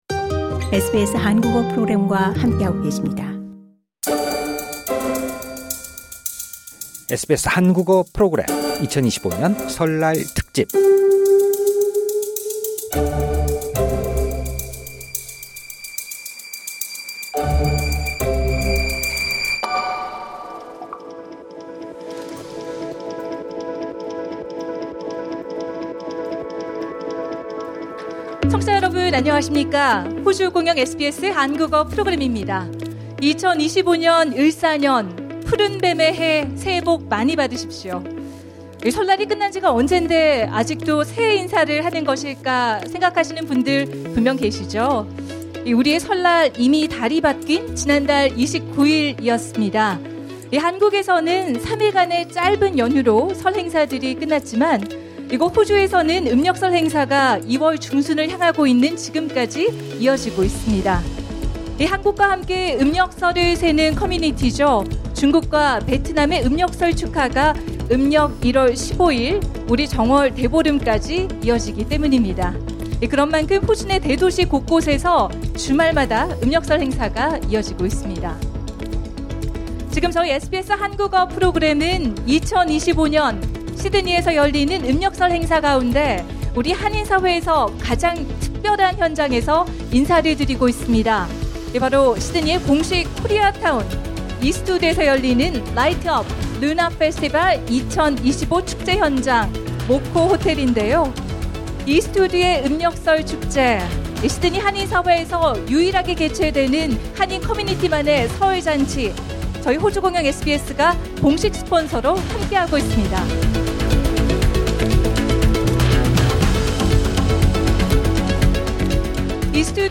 음력설을 맞아 이스트우드 코리아타운에서 열린 Light Up Lunar Festival 2025 축제 현장에서 SBS 한국어 프로그램이 공개 방송을 진행했습니다.